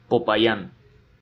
Popayán (Spanish pronunciation: [popaˈʝan]